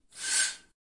用手指触摸金属百叶窗
描述：我拉着我的手指把它穿过一个金属百叶窗来发出这种声音。百叶窗在中途打开并从窗户拉开，这样就不会碰到它
Tag: 来袭 神秘的 百叶窗 手指 金属 Technica的 摩擦 OWI